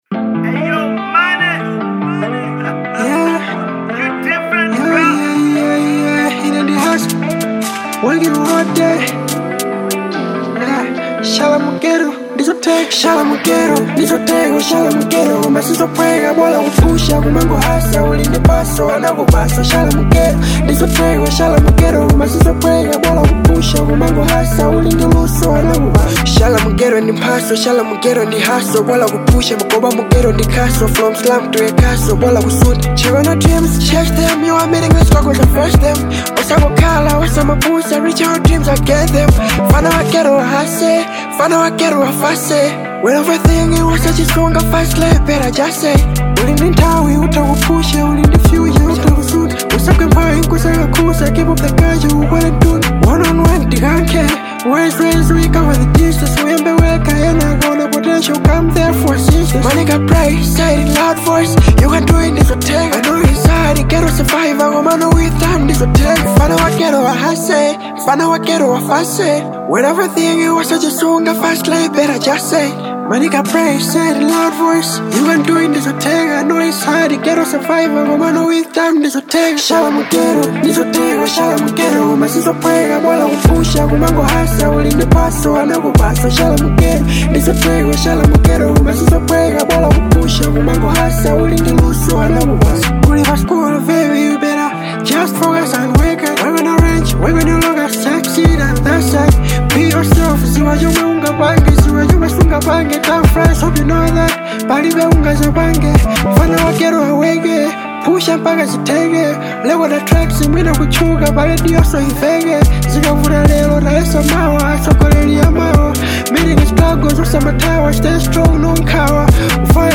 Genre : Hiphop/Rap